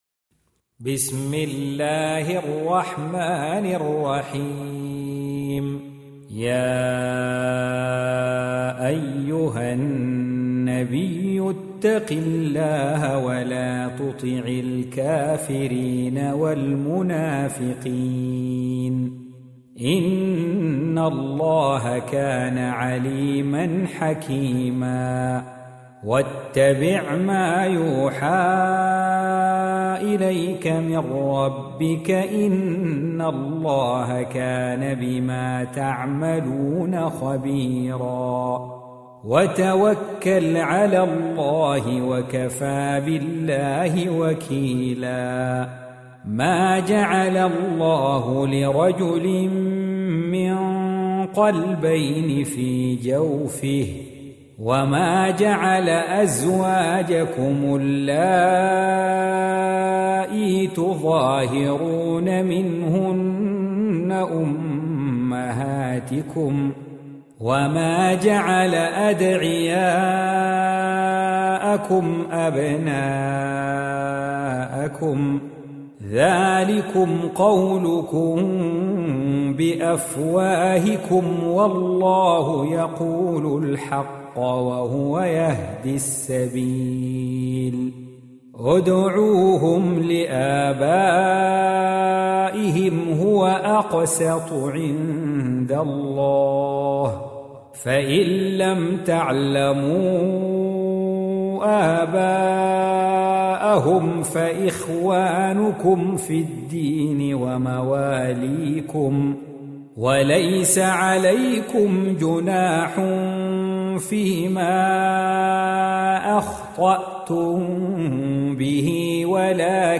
Surah Repeating تكرار السورة Download Surah حمّل السورة Reciting Murattalah Audio for 33. Surah Al�Ahz�b سورة الأحزاب N.B *Surah Includes Al-Basmalah Reciters Sequents تتابع التلاوات Reciters Repeats تكرار التلاوات